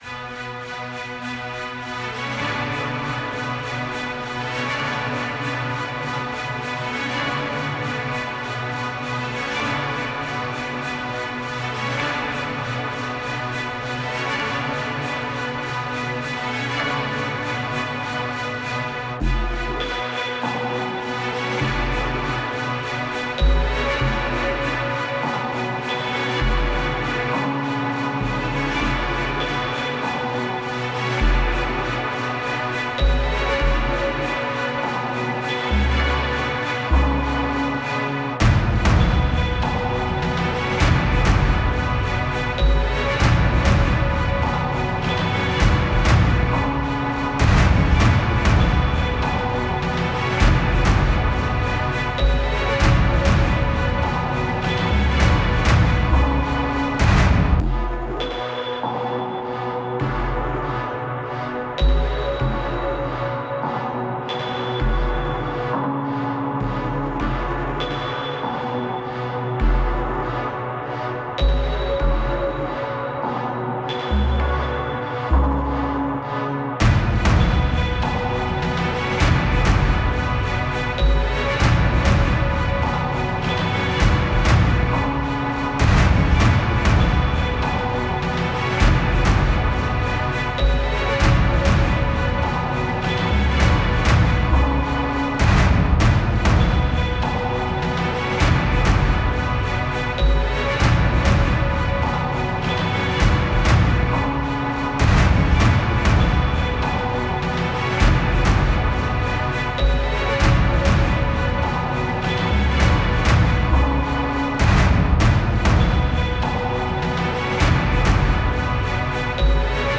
une musique d’horreur terrifiante et immersive